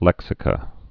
(lĕksĭ-kə)